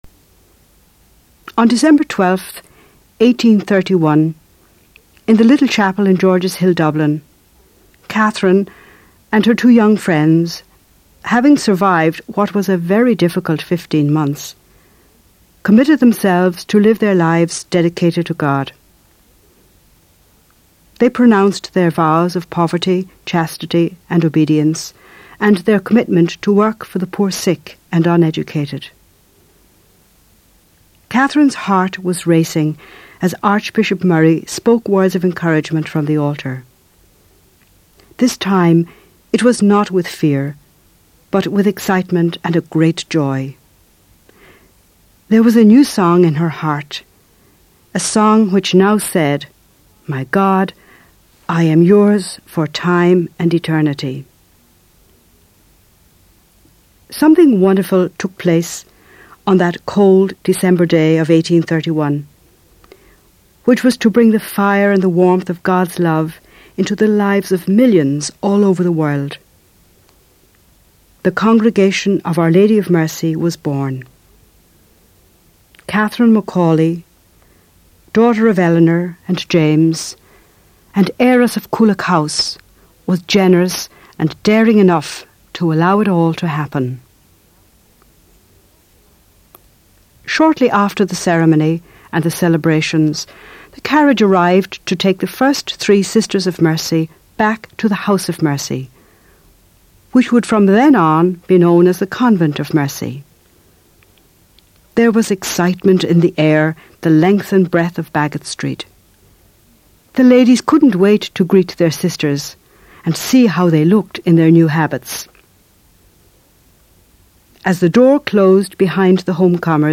The story of Catherine McAuley for younger listeners